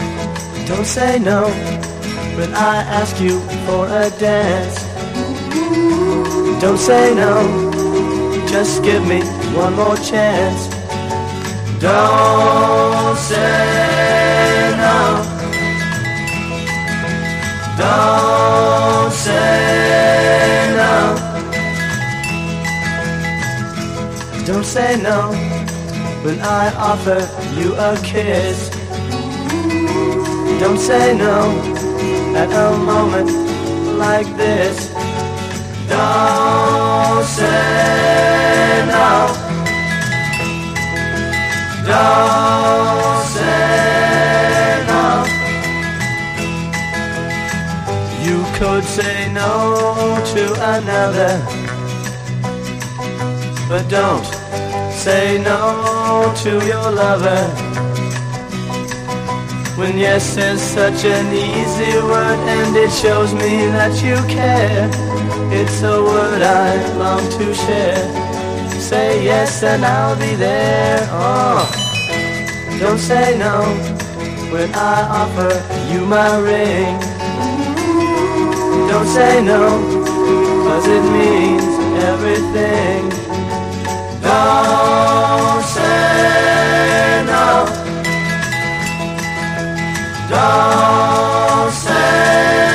フラットロックの王様！